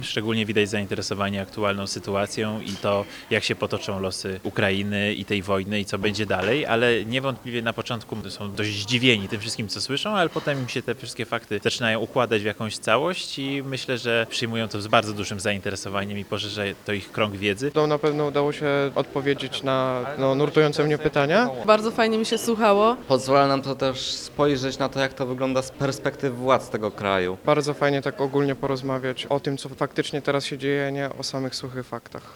Prelegentem był wojewoda łódzki Tobiasz Bocheński.
Lekcja dyplomacji z wojewodą łódzkim